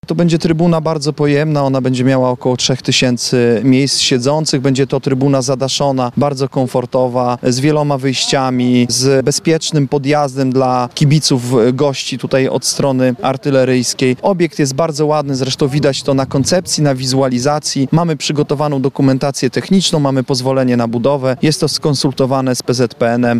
– Wybudowana zostanie też trybuna wschodnia – mówi zastępca prezydenta miasta Adam Chodziński.